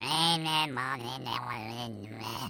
Звуки бормотания
Звук мультяшного героя, бормочущего себе под нос